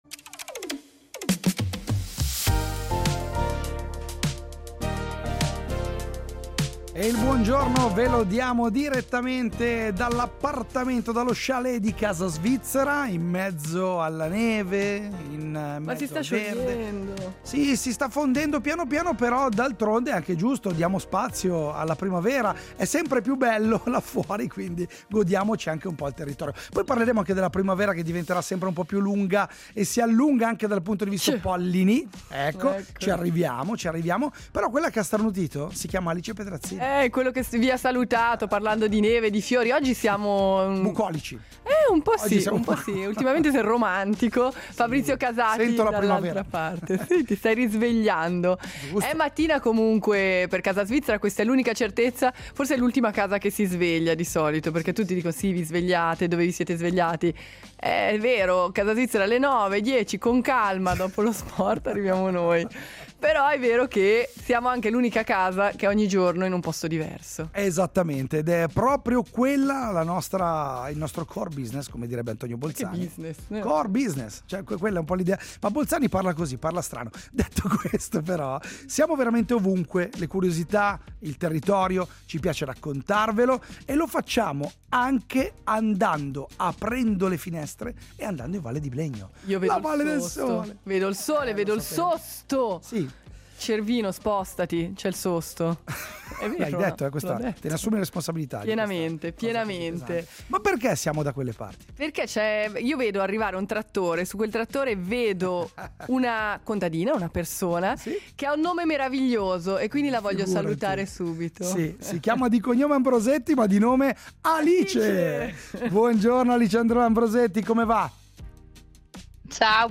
Mattina fresca, il rumore di un trattore nei prati e i pendii che lentamente tornano verdi sotto le montagne della Valle del Sole.